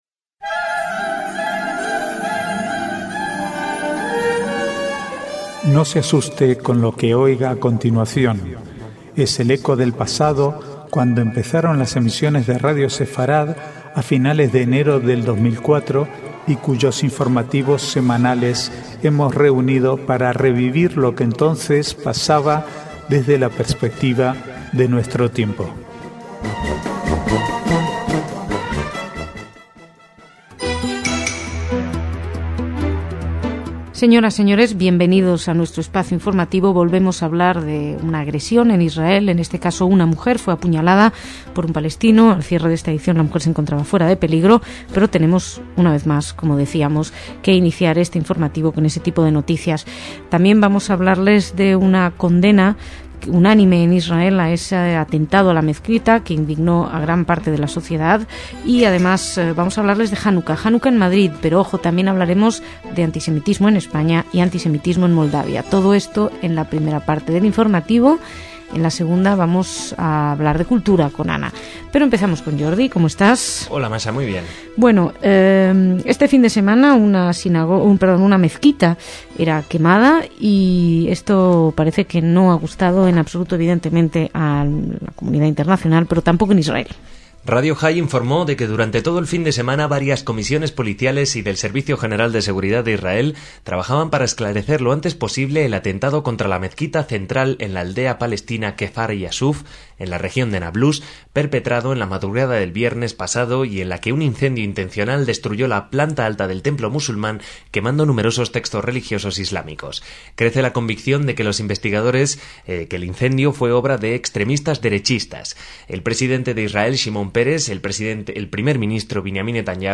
Archivo de noticias del 15 al 18/12/2009